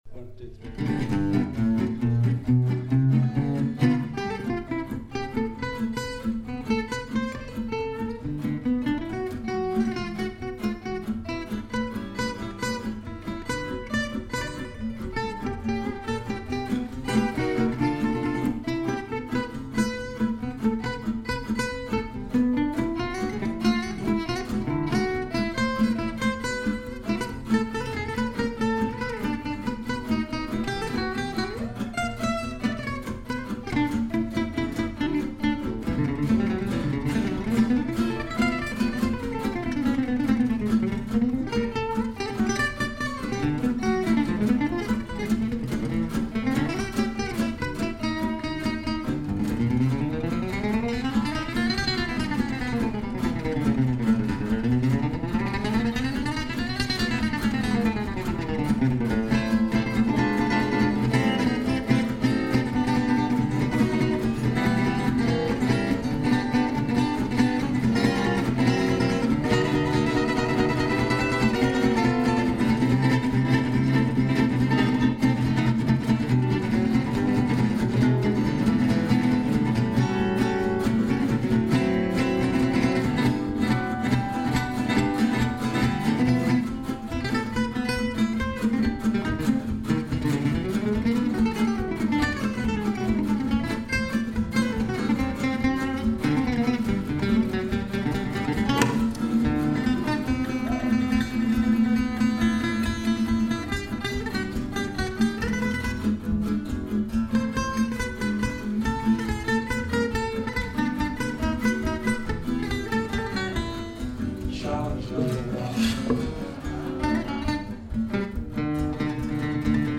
LIVE @ Thame Snooker Club
Rhythm Guitar
complete with impromptu string break